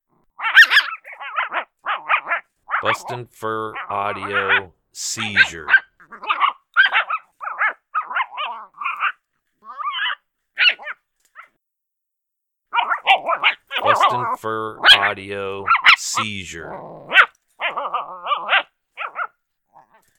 Young Coyote Pup in distress.
• Product Code: pups and fights